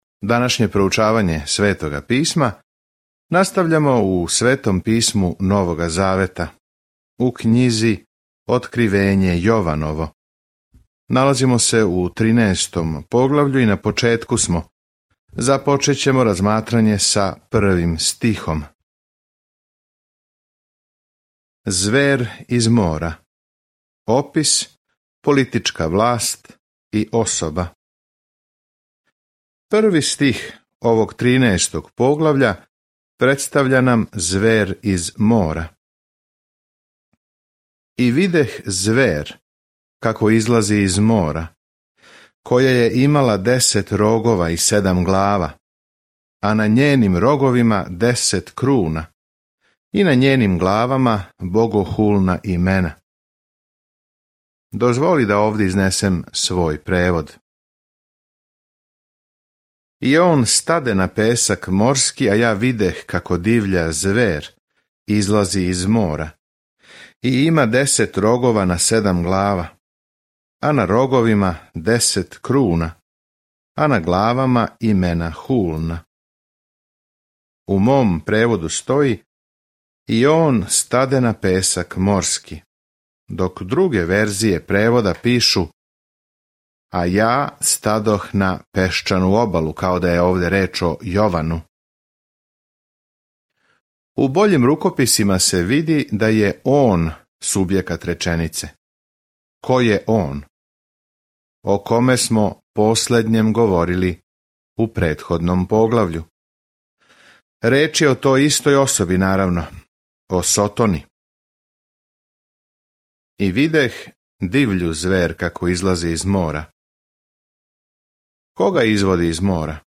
Scripture Revelation 13:2 Day 48 Start this Plan Day 50 About this Plan Откривење бележи крај свеобухватне временске линије историје са сликом о томе како ће се коначно обрачунати са злом и како ће Господ Исус Христ владати у свакој власти, моћи, лепоти и слави. Свакодневно путовање кроз Откривење док слушате аудио студију и читате одабране стихове из Божје речи.